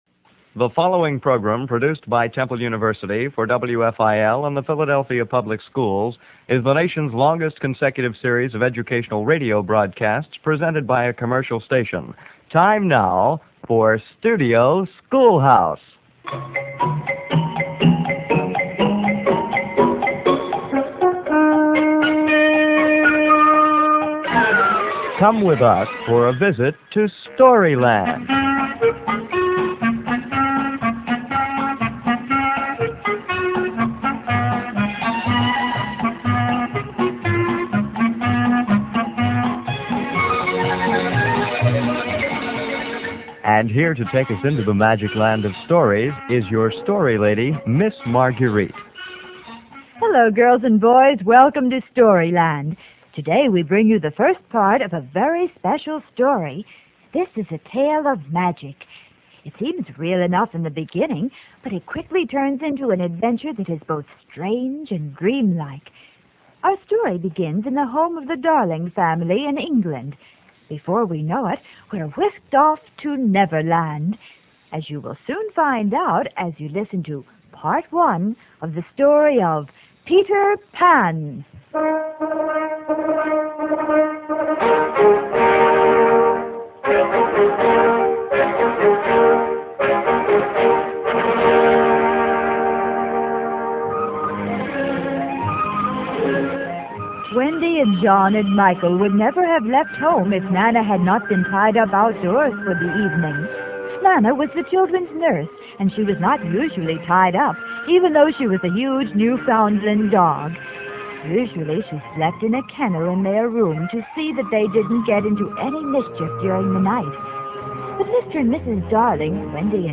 These 15-minute shows were taped about three weeks in advance in Studio A and were aired each weekday at 9:45am on WFIL.
The shows were basically live-to-tape.
The sound truck got a good workout on these three. Someone rocked back and forth in a creaky old chair for the boat, and the crocodile was an alarm clock and mike covered over with that old metal wash-tub from the sound effects cabinet.